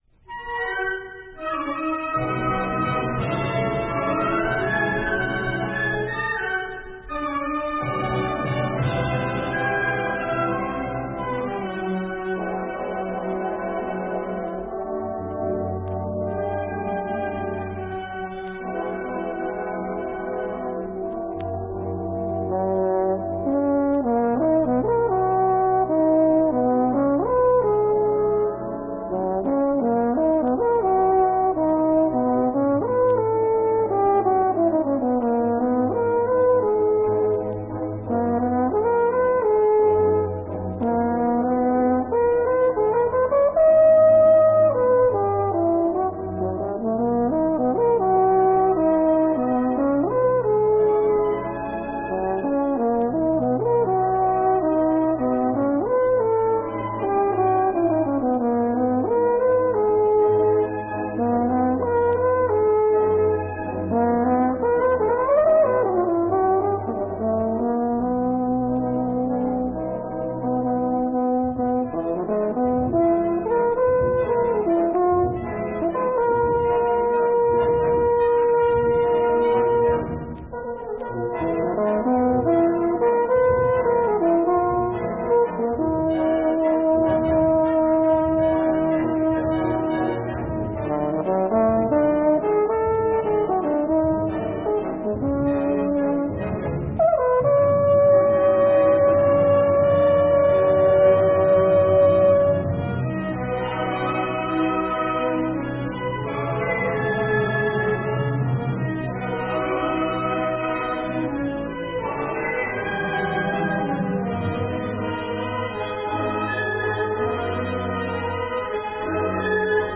Voicing: Flute Collection